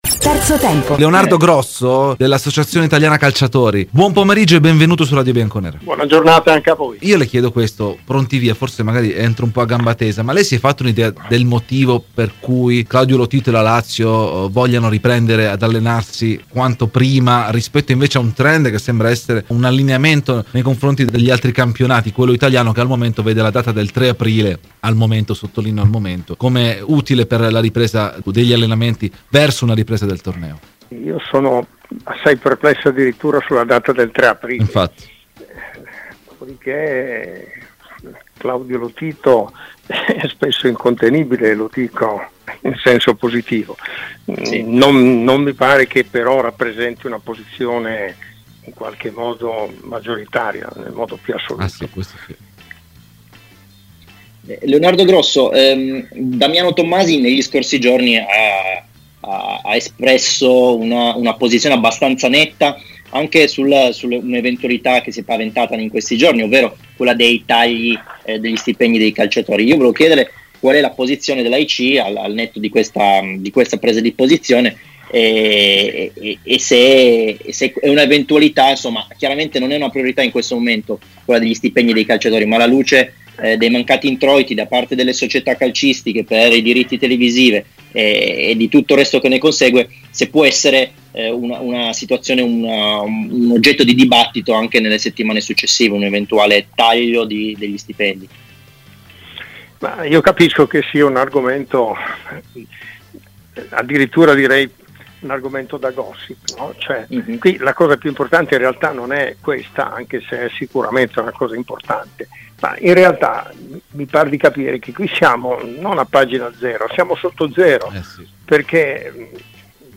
ai microfoni di "Terzo Tempo"